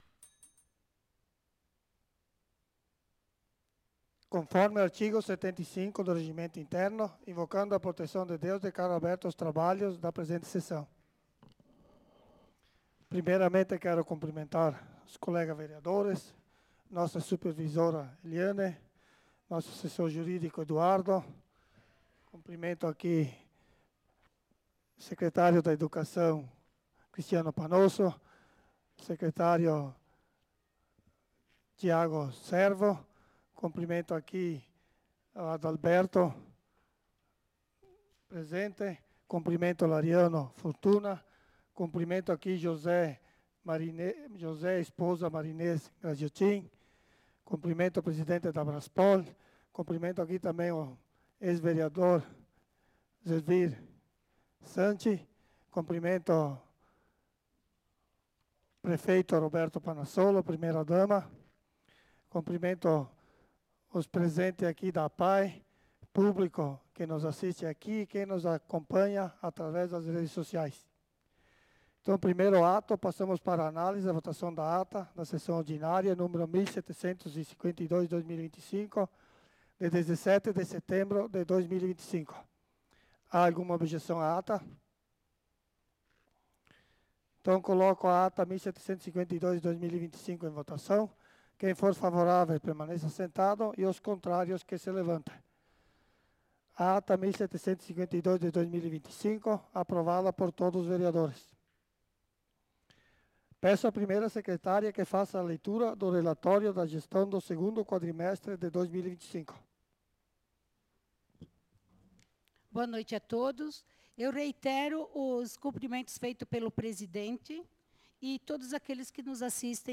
Sessão Ordinária do dia 24/09/2025
Leitura de comunicados